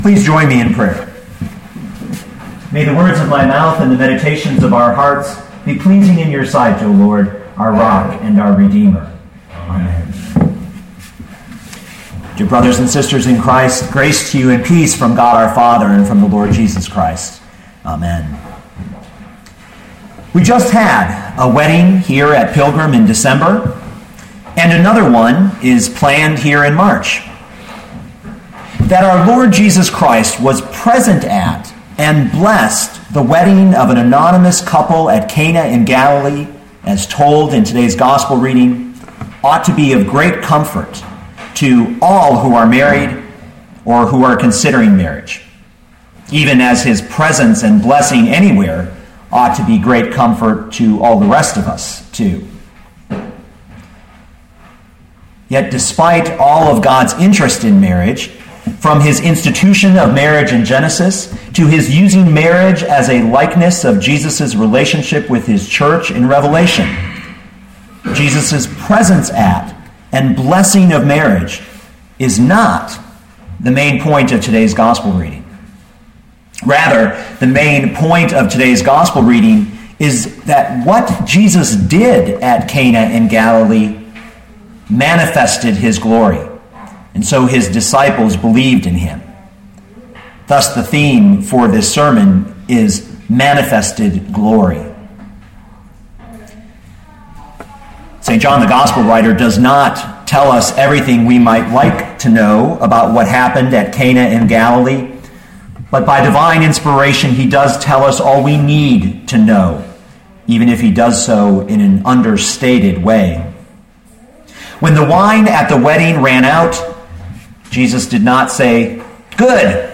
2013 John 2:1-11 Listen to the sermon with the player below, or, download the audio.